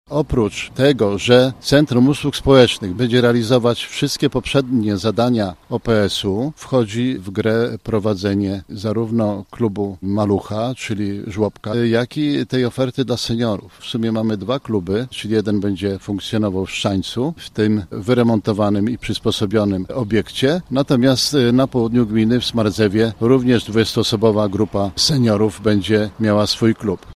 – Ta nowa instytucja jeszcze w tym roku zastąpi nasz Ośrodek Pomocy Społecznej i ma koordynować pracę dla mieszkańców w zakresie polityki społecznej i socjalnej – tłumaczy Krzysztof Neryng, wójt gminy Szczaniec.